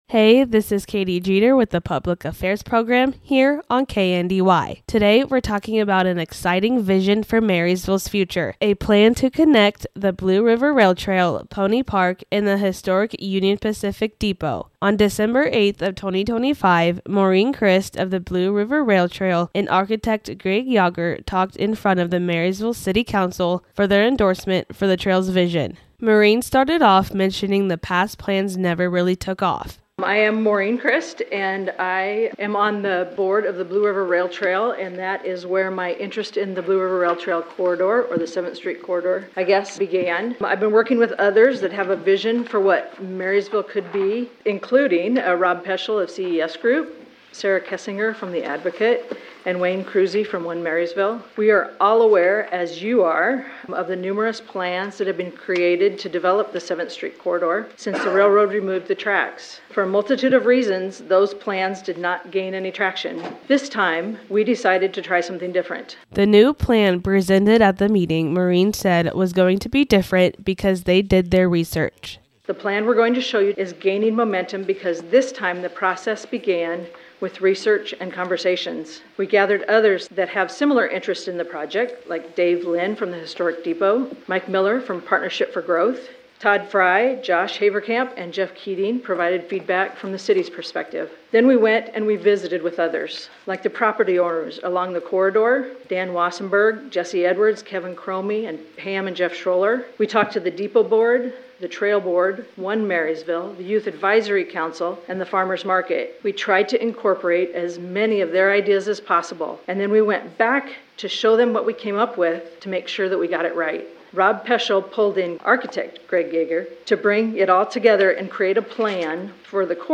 KNDY NEWS PODCAST